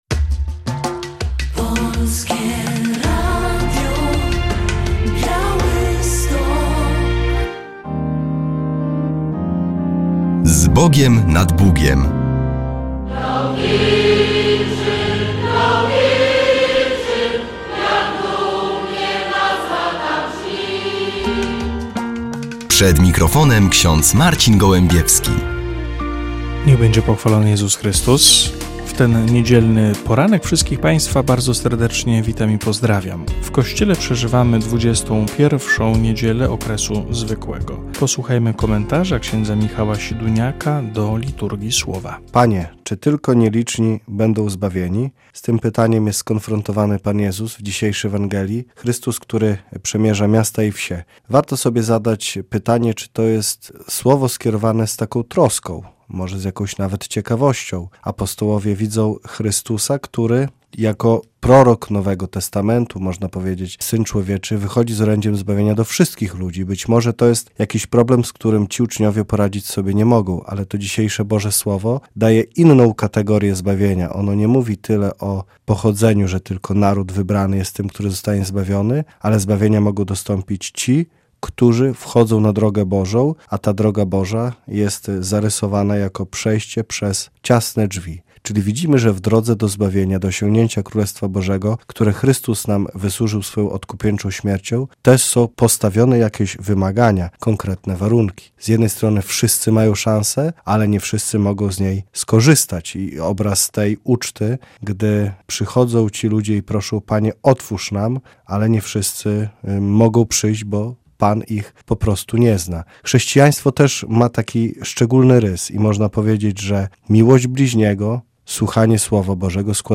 W audycji relacja z rozpoczęcie V Drohiczyńskiej Pielgrzymki Rowerowej na Jasną Górę.